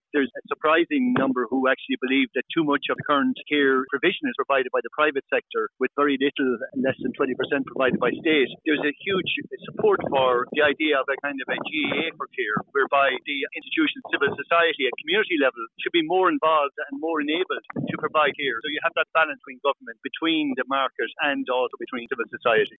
Mark Mellett, Chairman of Sage Advocacy says more than eight in 10 of those surveyed think home care is overly concentrated on private providers…………….